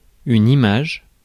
Ääntäminen
IPA: /i.maʒ/